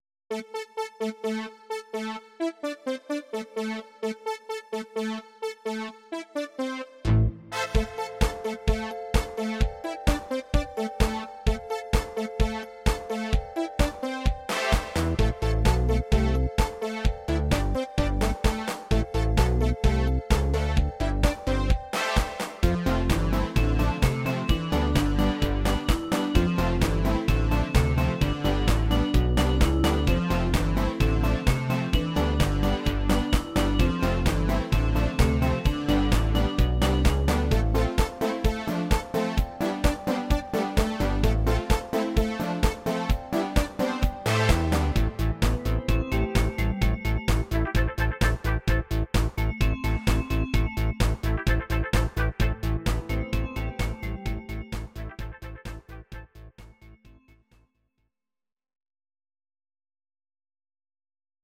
Audio Recordings based on Midi-files
Pop, Ital/French/Span, 1980s